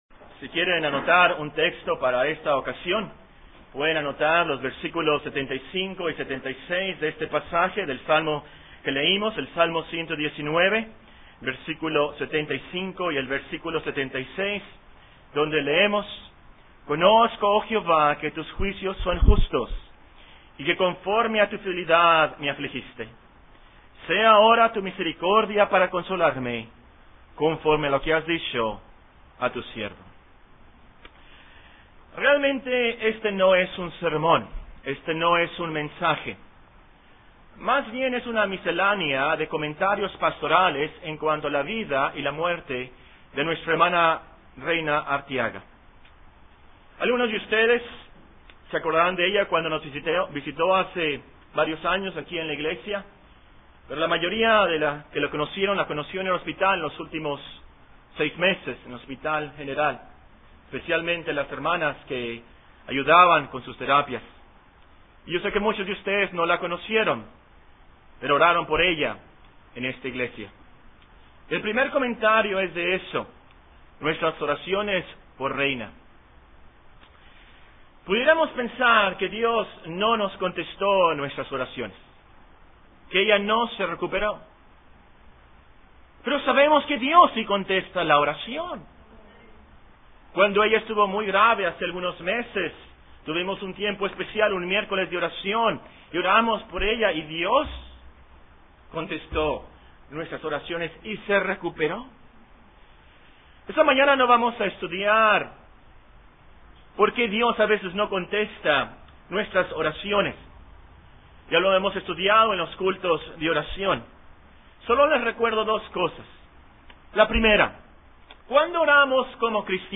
Serie de sermones General